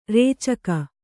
♪ rēcaka